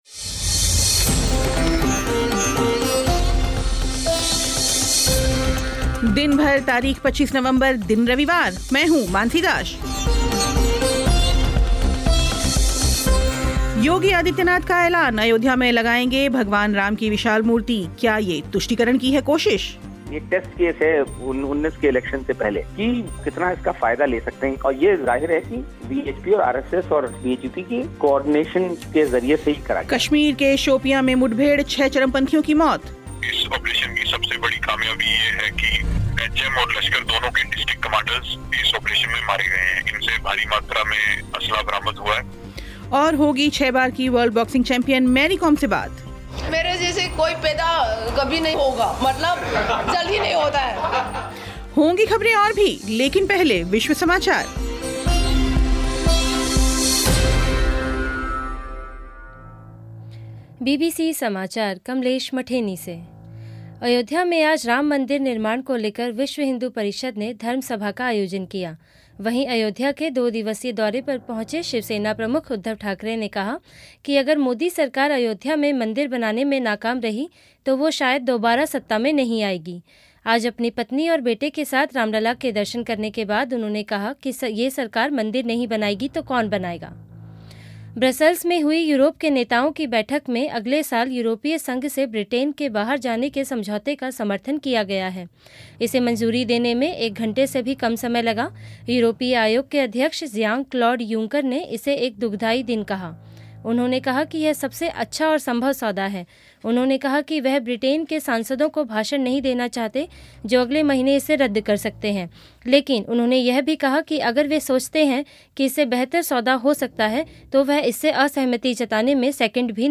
छह बार की वर्ल्ड बॉक्सिंग चैंपियन मैरी कॉम से बात